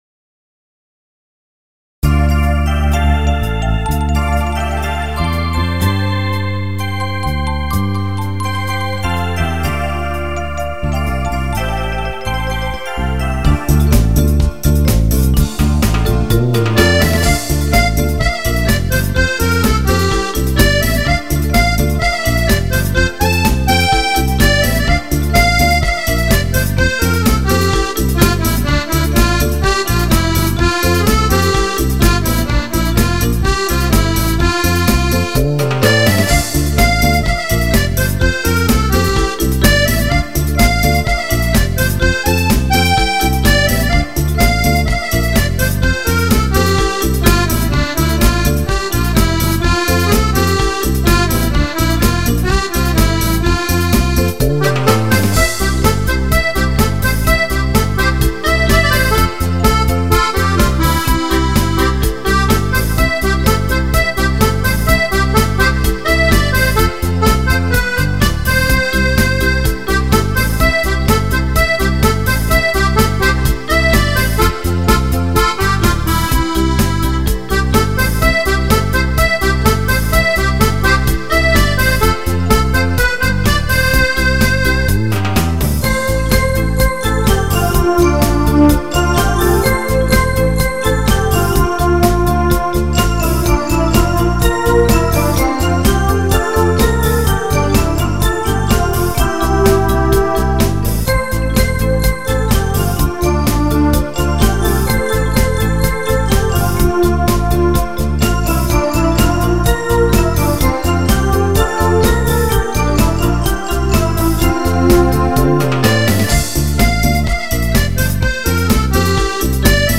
Ballo di gruppo